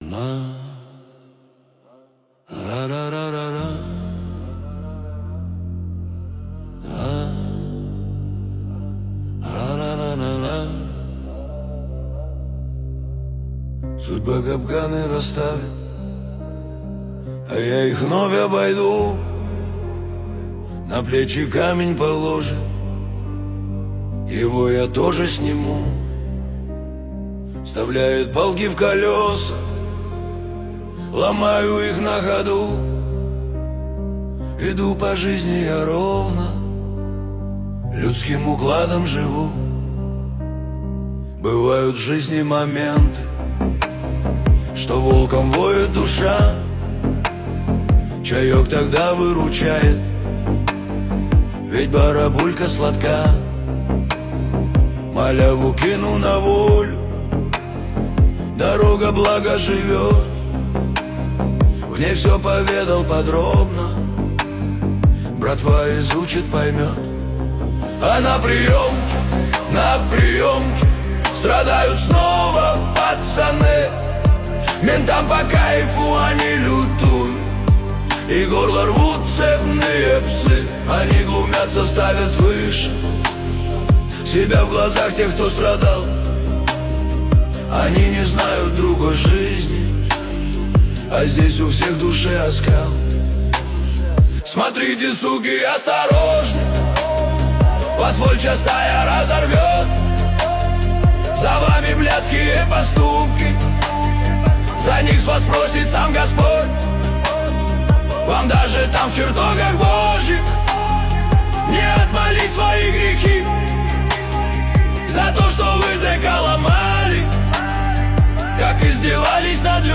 Зарубежный рок